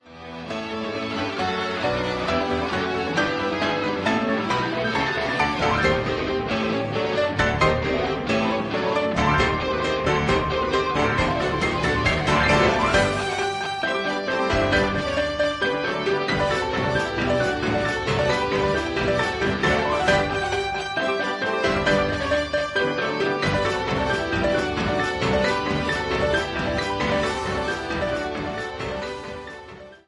The live piano accompaniment that plays with the album
Instrumental